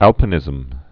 (ălpə-nĭzəm)